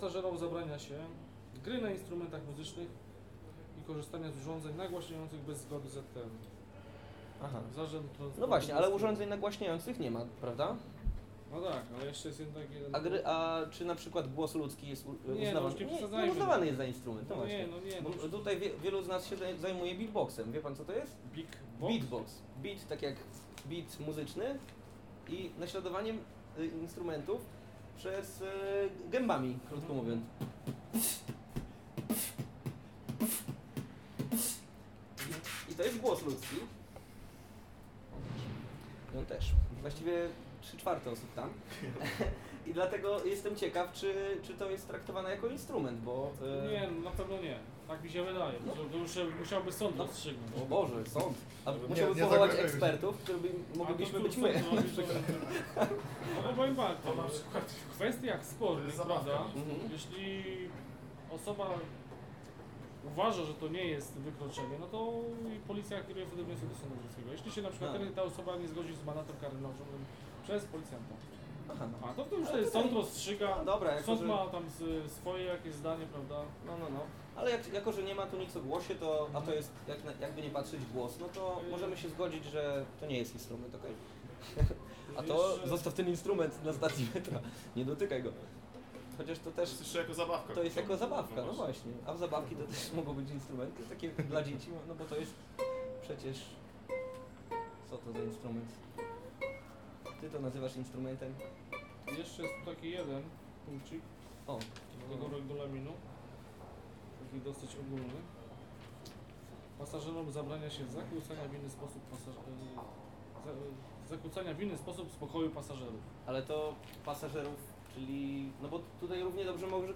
Mała ściągawka z regulaminu metra odczytana osobiście przez praworządnych tego miasta:) Jak się okazuje, jesteśmy na legalu!